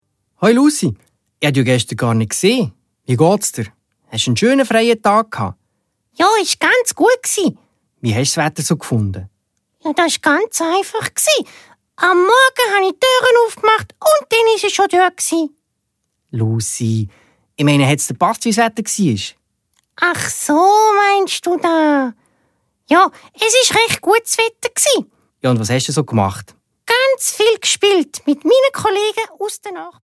Laufzeit: 69 Minuten, Schweizerdeutsch